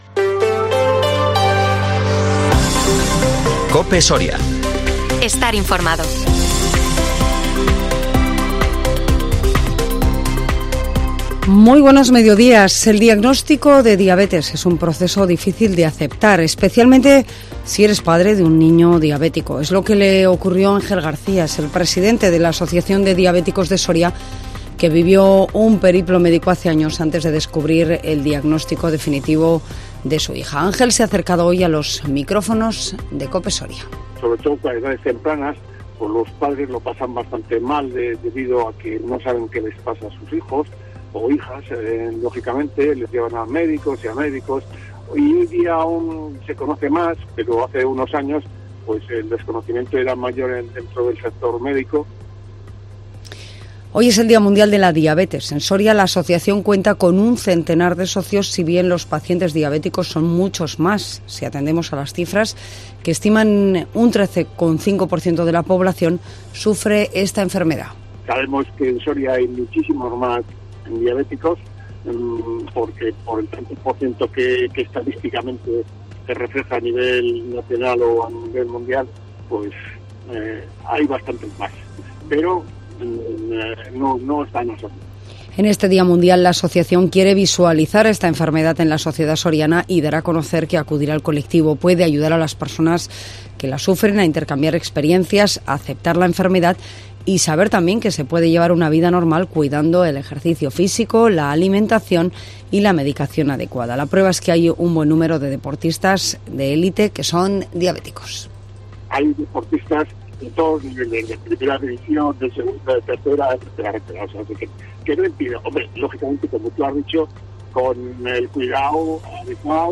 AUDIO: Las noticias en COPE Soria